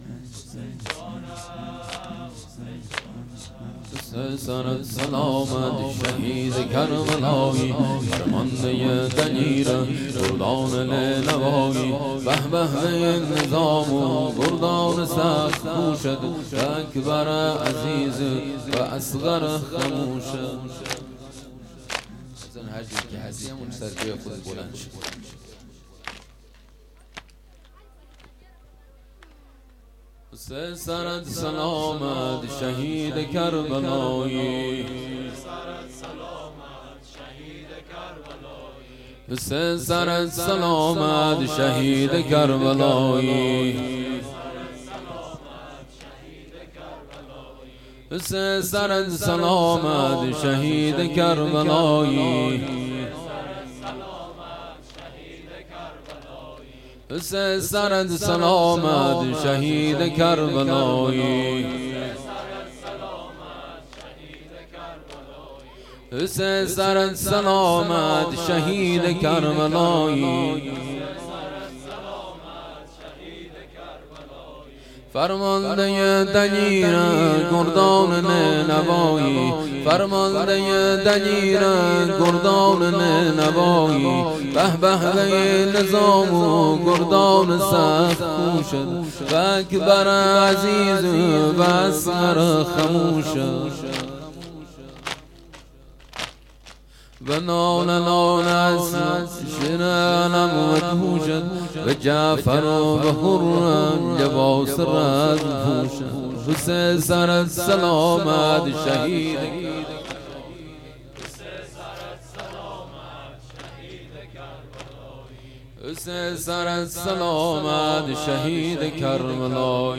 محرم ۱۳۹۵
مداحی لری (حسین سرت سلامت